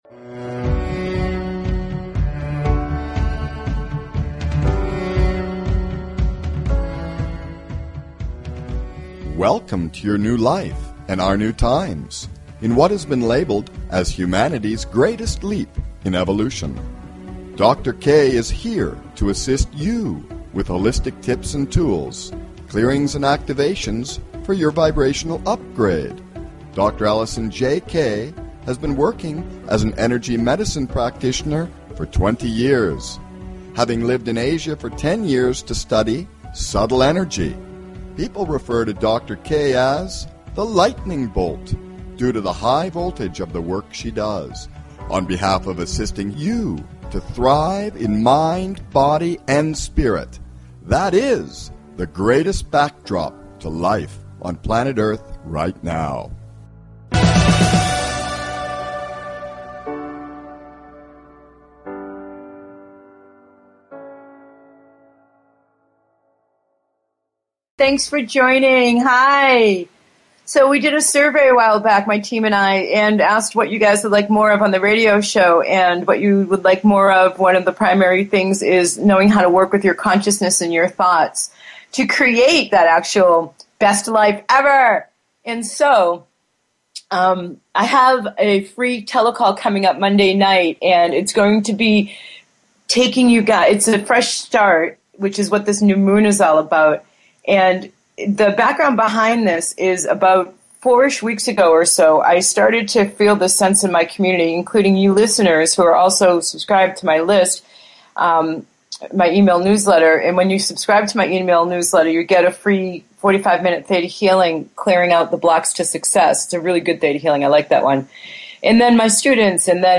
Talk Show Episode, Audio Podcast, Vibrational Upgrade and Saying YES to MORE and no to less! on , show guests , about Genuinely Desire,False Limitations,Yes to More, categorized as Health & Lifestyle,Kids & Family,Philosophy,Psychology,Self Help,Spiritual